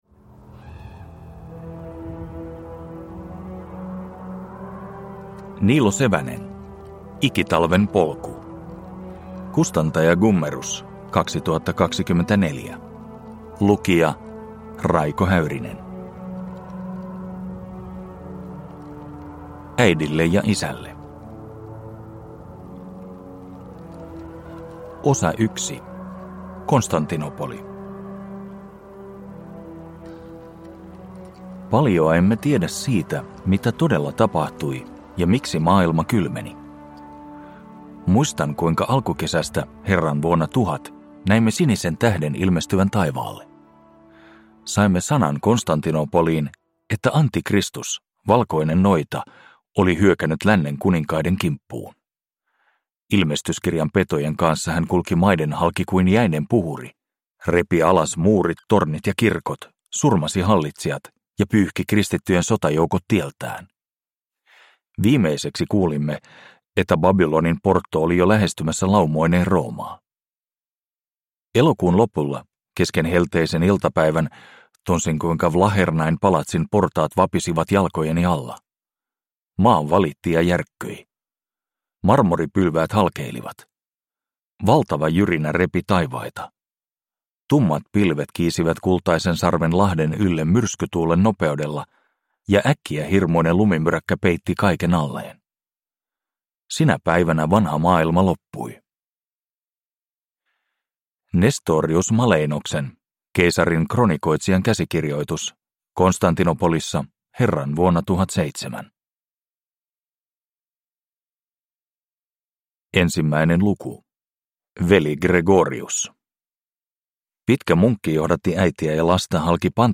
Ikitalven polku – Ljudbok